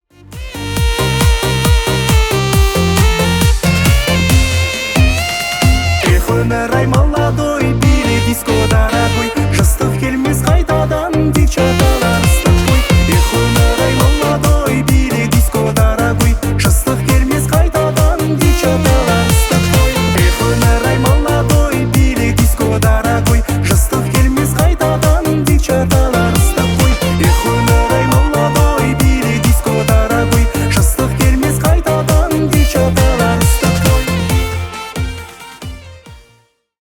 • Качество: 320 kbps, Stereo
Казахские
весёлые